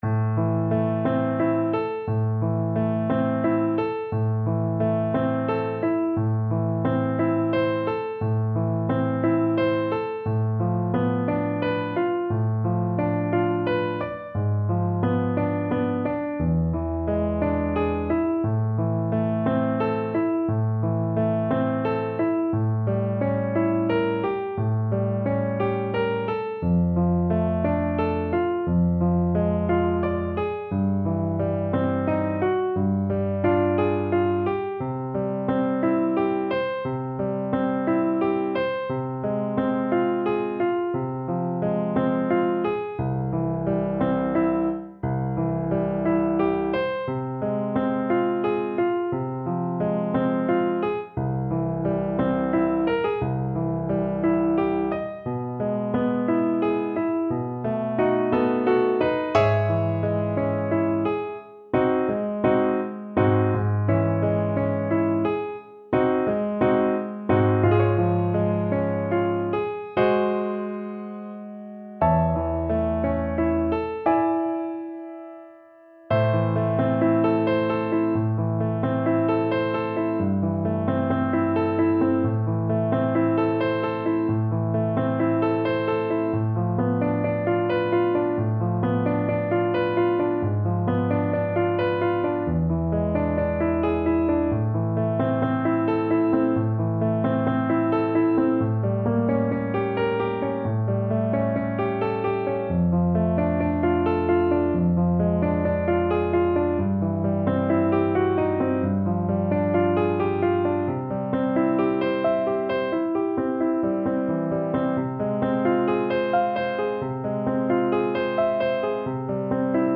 ~ = 88 Malinconico espressivo
A minor (Sounding Pitch) (View more A minor Music for Flute )
3/4 (View more 3/4 Music)
Classical (View more Classical Flute Music)